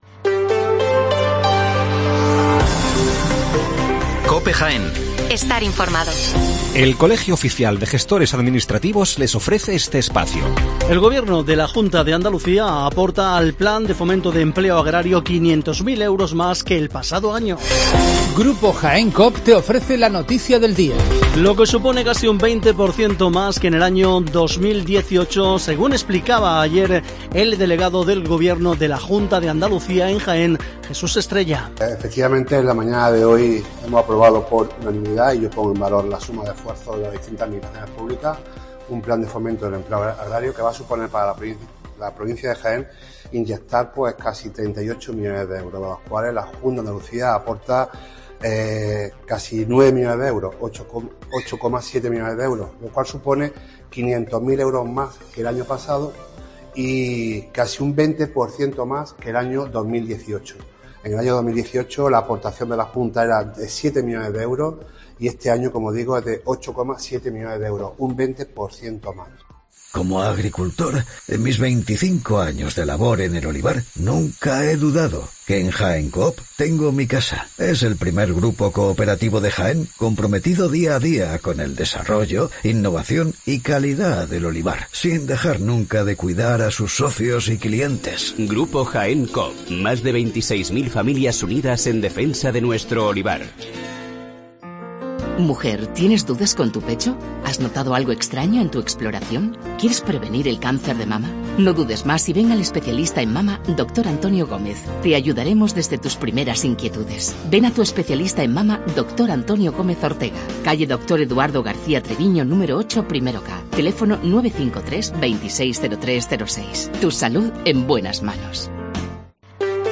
Las noticias matinales en Herrera en COPE 7:55 horas